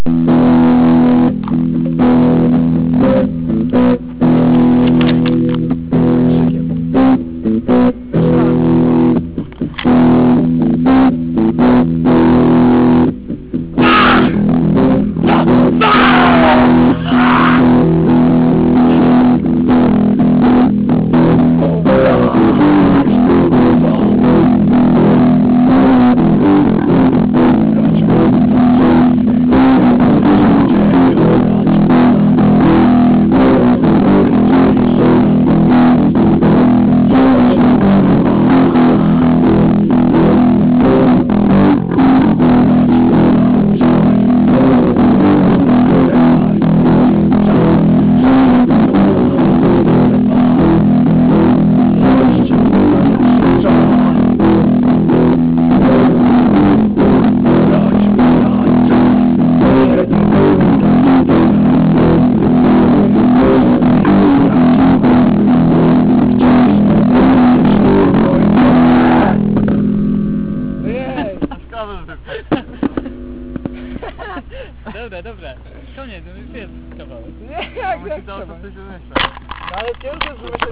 zagrał po raz pierwszy w trzyosobowym składzie
gitara
wokal